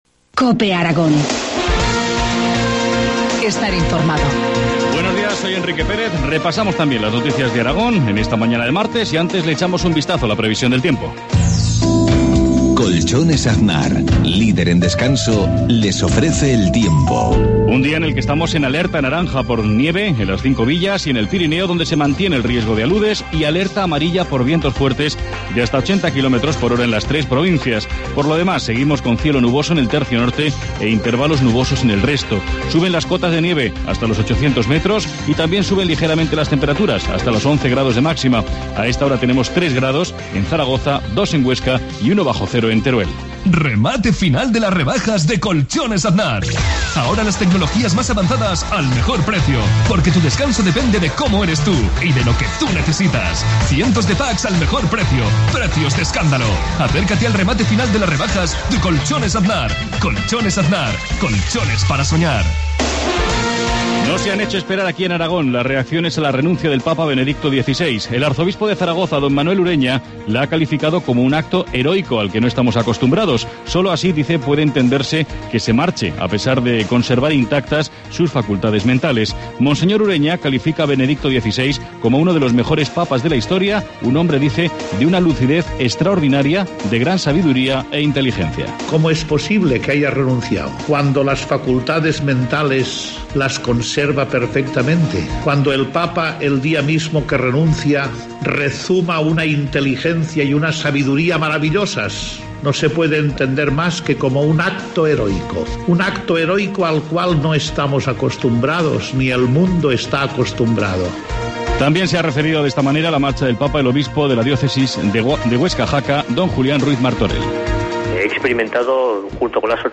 Informativo matinal, martes 12 de febrero, 7.53 horas